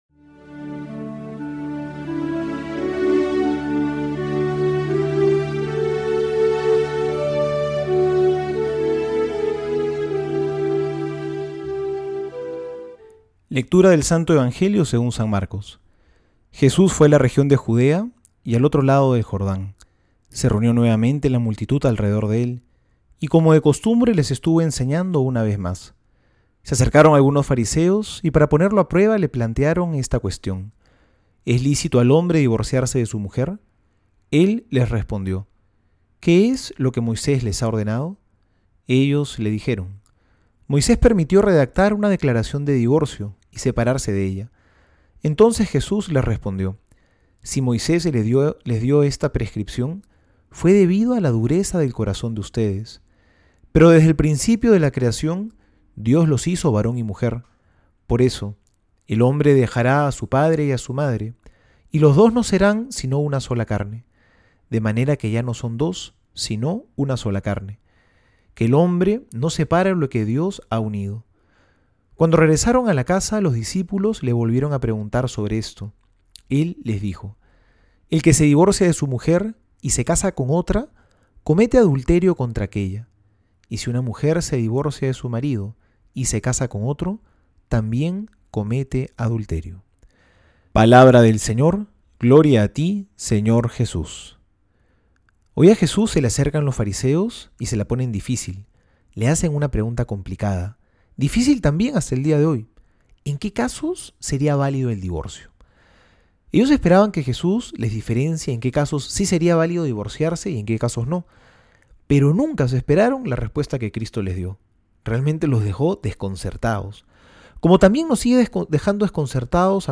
Homilía para hoy: